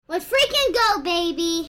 Lets freaking go baby sound button getting viral on social media and the internet Here is the free Sound effect for Lets freaking go baby that you can